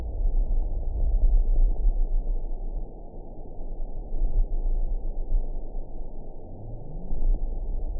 event 922612 date 02/09/25 time 10:17:59 GMT (2 months, 3 weeks ago) score 6.65 location TSS-AB10 detected by nrw target species NRW annotations +NRW Spectrogram: Frequency (kHz) vs. Time (s) audio not available .wav